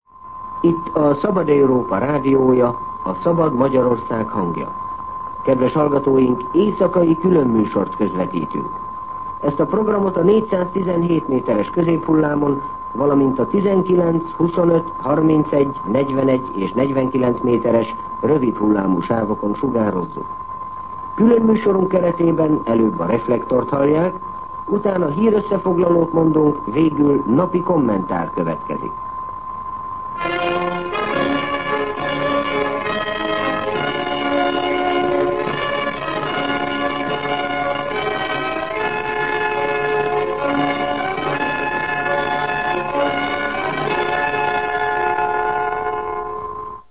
Szignál